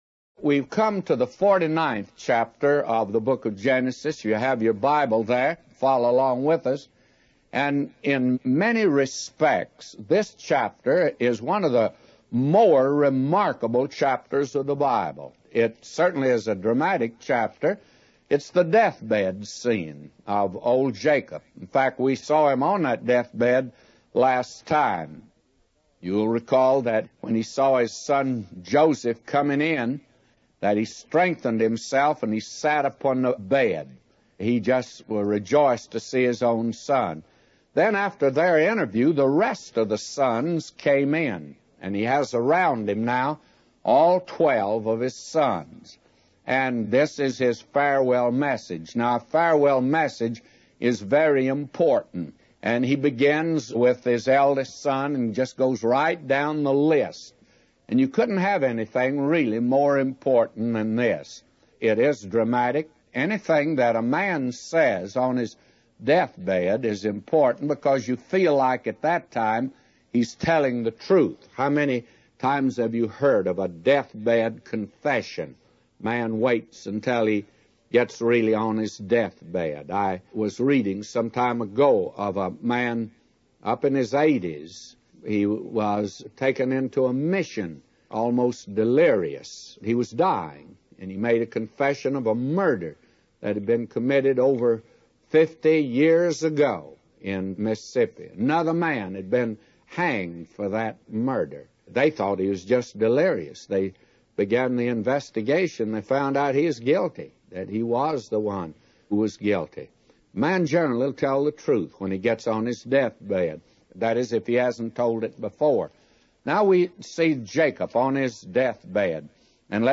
A Commentary By J Vernon MCgee For Genesis 49:1-999